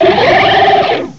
Add all new cries
cry_not_quilladin.aif